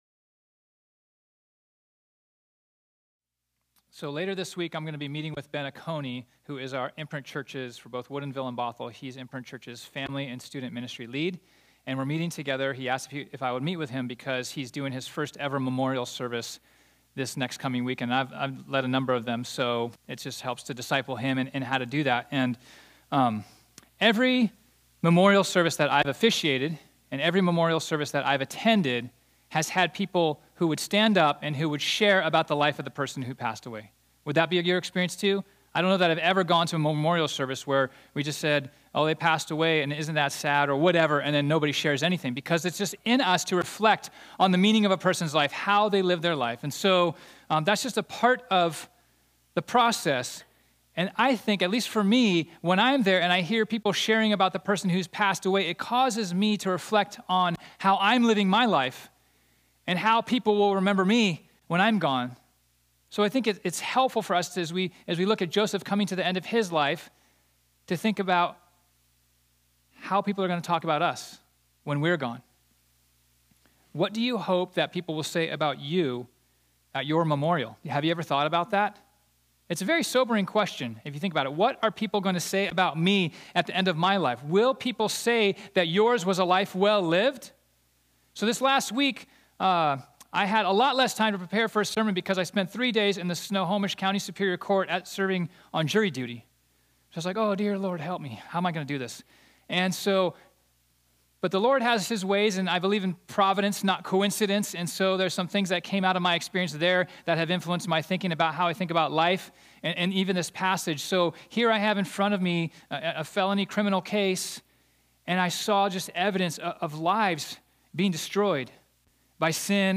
This sermon was originally preached on Sunday, June 16, 2019.